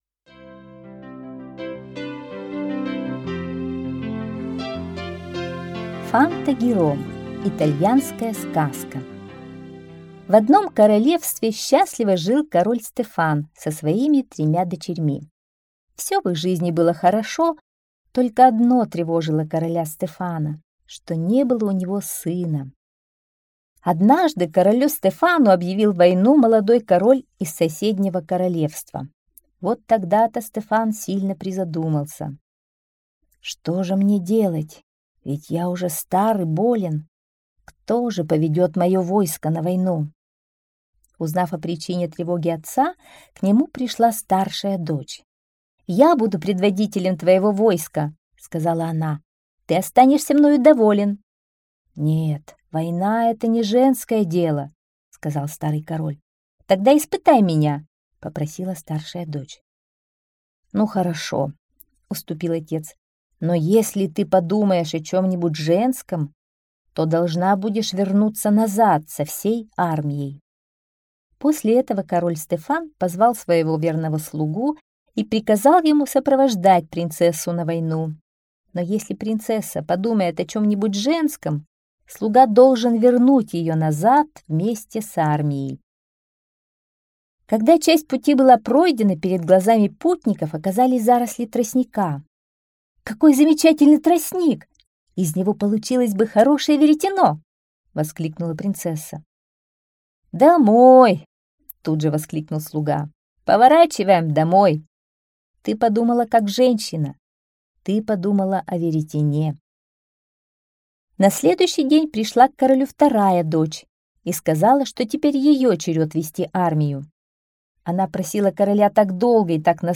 Фанта-Гиро - итальянская аудиосказка - слушать онлайн